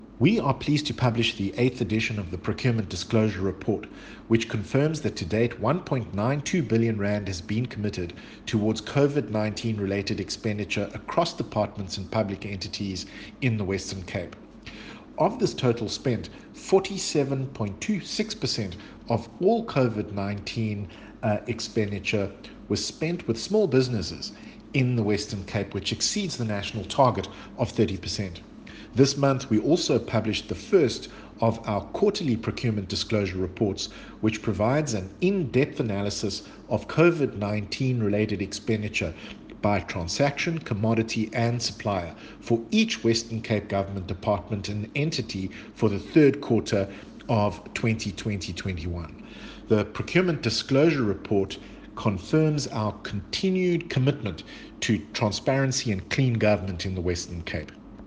Listen to soundbyte from Minister David Maynier: Soundbyte_David Maynier_210302.mp3 (mp3, 2.22 MB)